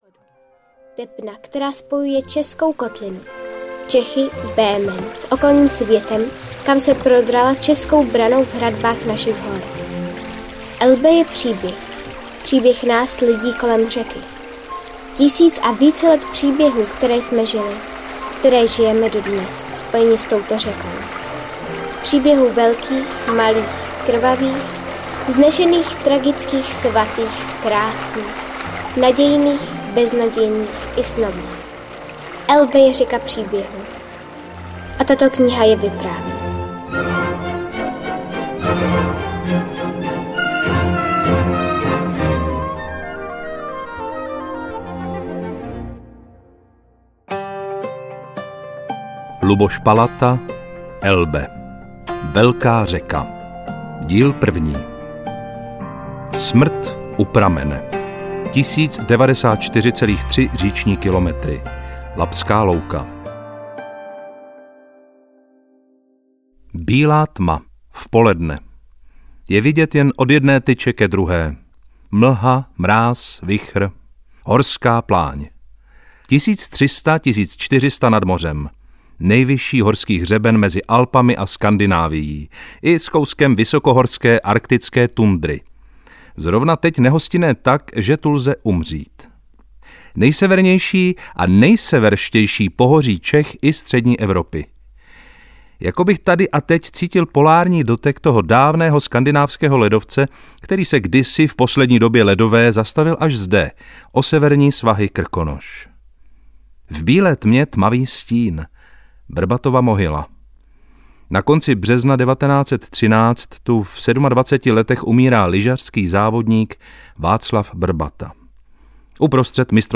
Elbe, velká řeka audiokniha
Ukázka z knihy